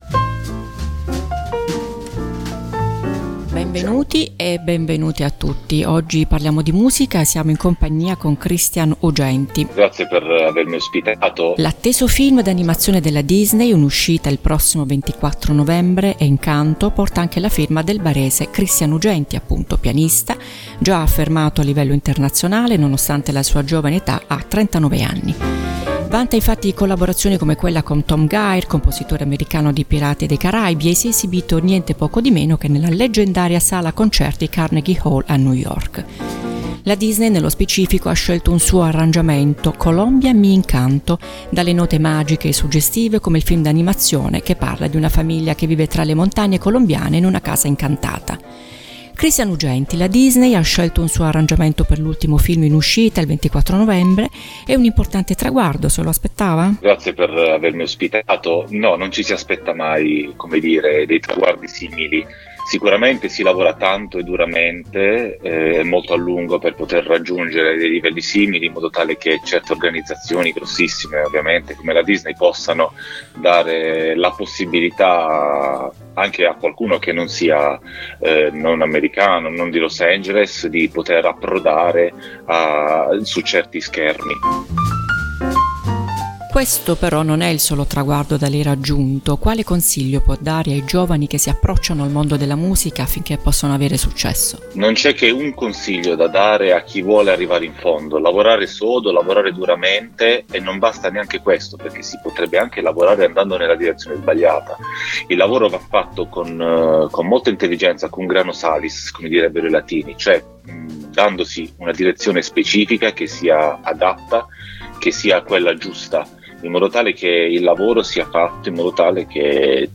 Ep1 - Intervista